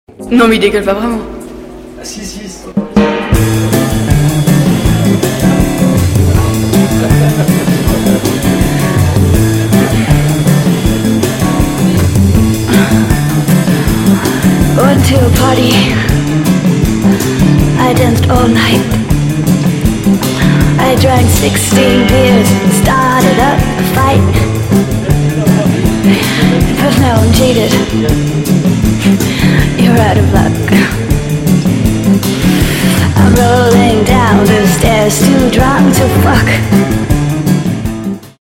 DJ铃声 大小
funky music
fucky女唱 DJ舞曲